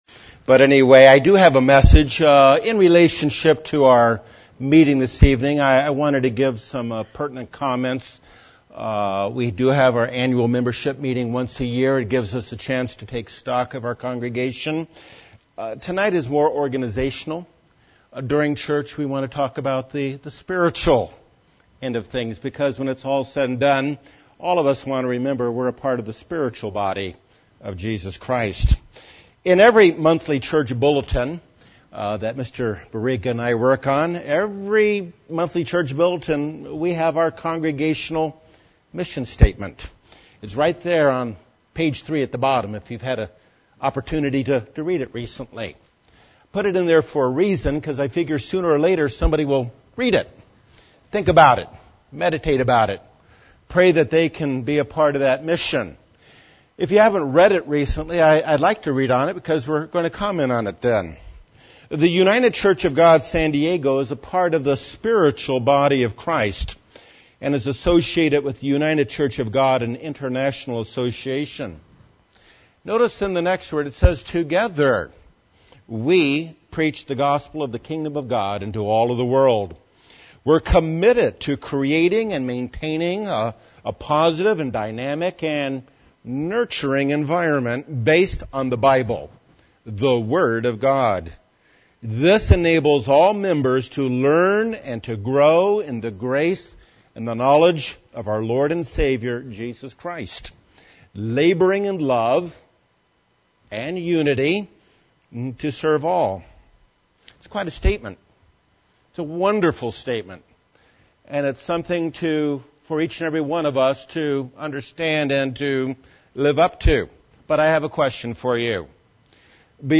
We should open our Bibles, open our hearts, be open to changes, and be open to Jesus Christ living in us, UCG Sermon Transcript This transcript was generated by AI and may contain errors.